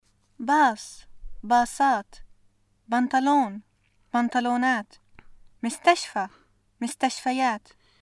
[baaS (baaSaat), banTaloon (banTloonaat), məstaʃfa (məstaʃfayaat)]